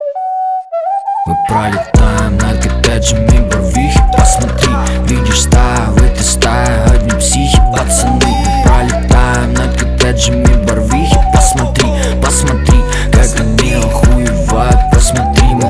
• Качество: 320, Stereo
ритмичные
Хип-хоп